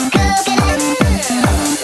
coconut-3.mp3